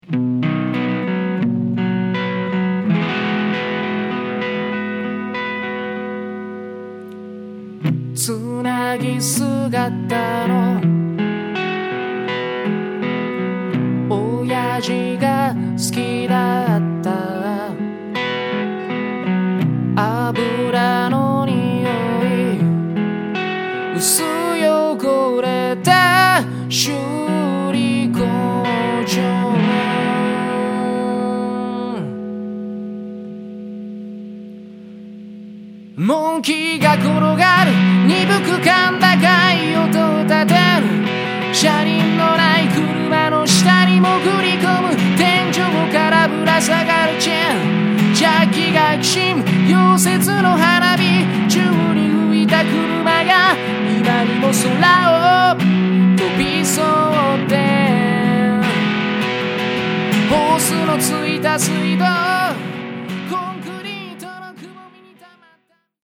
ボーカルがいいですねえ。ギター１本でしっかりロックしてるけど日本語がちゃんと伝わってくる。
ブルースの歌詞のようでもあり、フォーク、ロックの歌詞のようでもあって、郷愁をそそります。ギターの音色もいかしてる。